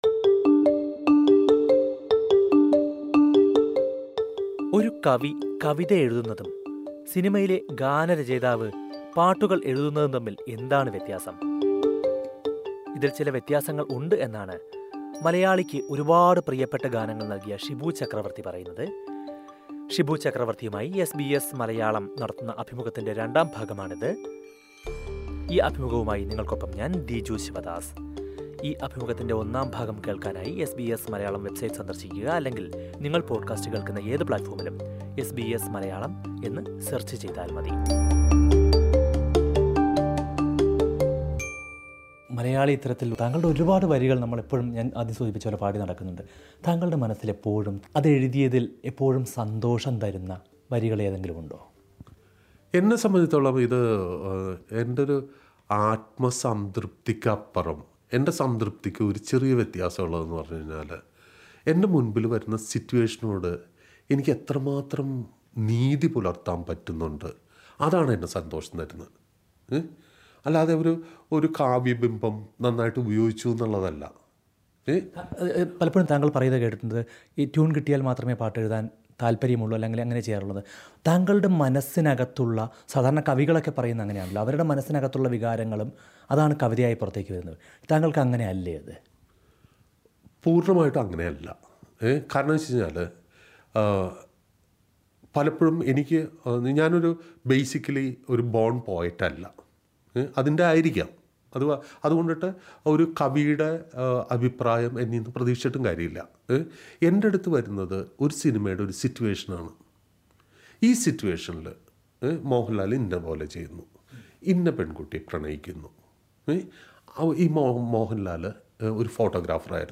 കവിതയും സിനിമാഗാനവും തമ്മില്‍ എന്താണ് വ്യത്യാസം? സിനിമയിലെ രംഗത്തിനും, ട്യൂണിനുമെല്ലാം ഒപ്പിച്ച് പാട്ടെഴുതുന്നത് എങ്ങനെയാണെന്ന് വിശദീകരിക്കുകയാണ് പ്രശസ്ത ഗാനരചയിതാവ് ഷിബു ചക്രവര്‍ത്തി. ഷിബു ചക്രവര്‍ത്തിയുമായി എസ് ബി എസ് മലയാളം നടത്തിയ സംഭാഷണത്തിന്റെ രണ്ടാം ഭാഗം കേള്‍ക്കാം, മുകളിലെ പ്ലേയറില്‍ നിന്ന്...